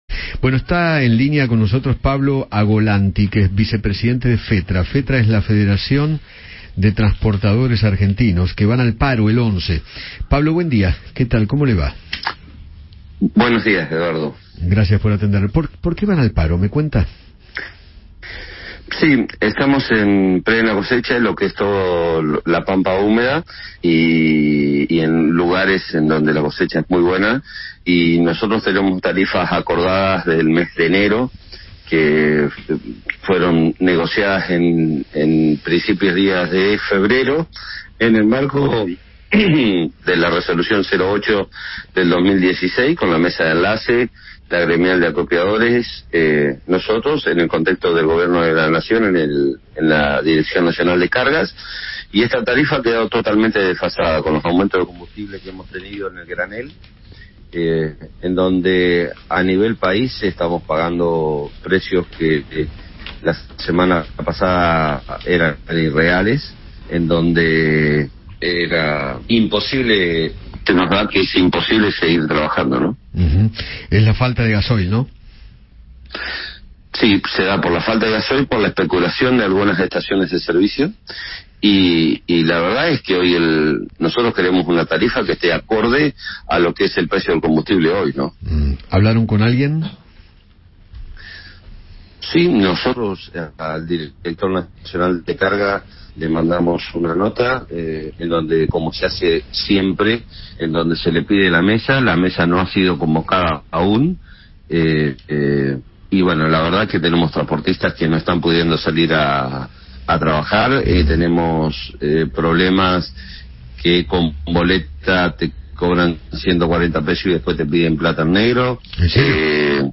conversó con Eduardo Feinmann sobre la medida de fuerza que realizarán el lunes 11 en reclamo de una actualización de tarifas en el sector